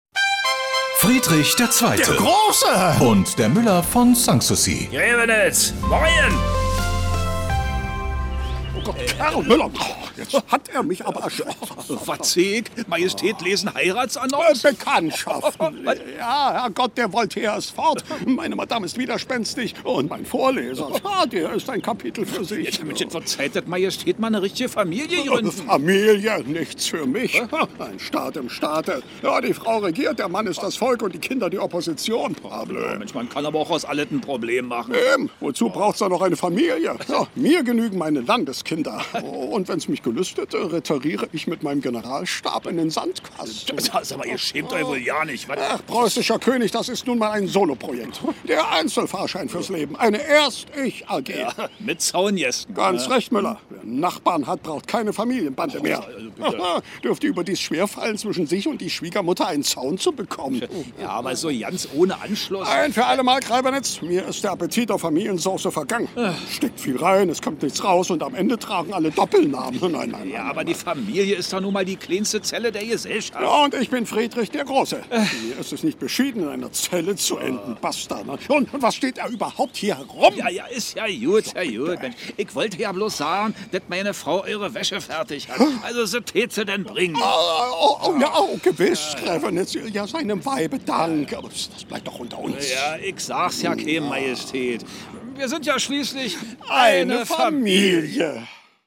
… continue reading 26 حلقات # Komödie # Antenne Brandenburg, Rundfunk berlin-Brandenburg, Germany # Antenne Brandenburg # Rundfunk Berlin-brandenburg